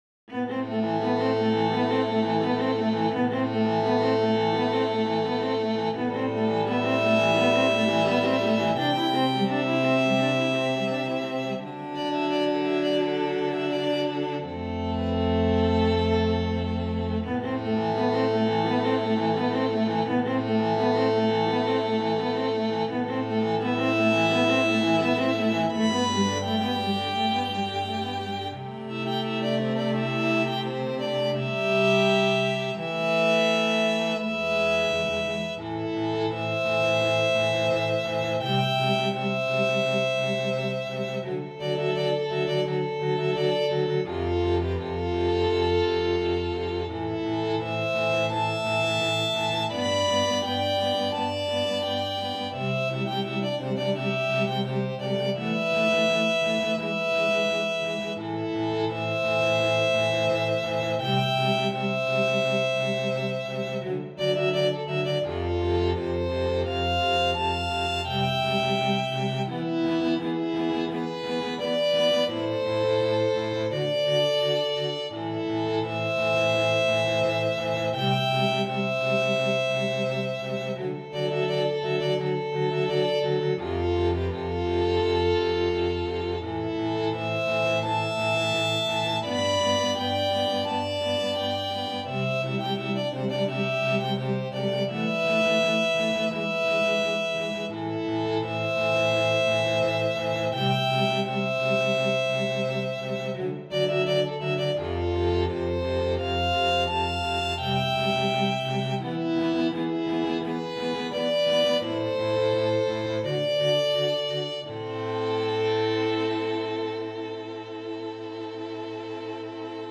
● Violino I
● Violino II
● Viola
● Violoncelo